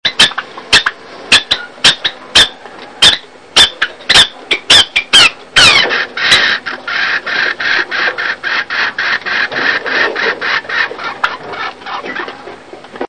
・・・寡婦ソウのふりしぼるような歌声
夫亡き後、何を思ったのか、自らさえずりもどきをするようになったソウ。